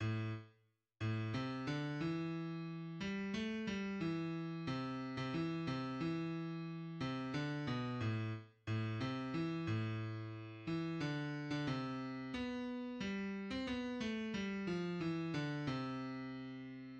{\clef bass \tempo 4=90 \time 3/4 \set Score.currentBarNumber = #1 \bar "" a,8 r4 a,8 c d e4. g8 a g e4 c8. c16 e8 c e4. c8 d b, a, r8 a, c e a,4. e8 d8. d16 c4 b g8. c'16 b8(a g) f e d c2 }\addlyrics {\set fontSize = #-2 - NIT ZUKH MIKH VU DI MIR- TN GRI- NEN GE- FINST MIKH DOR- TN NIT, MAYN SHATS. VU LE- BNS VEL- KN BAY MA-SHI-NEN, DOR- TN IZ- - MAYN RU- E PLATS. } \midi{}